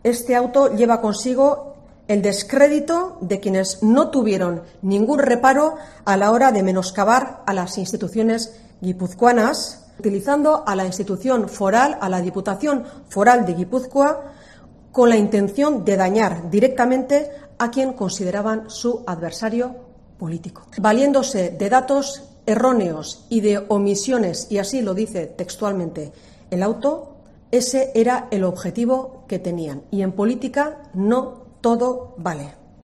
Eider Mendoza, diputada general de Gipuzkoa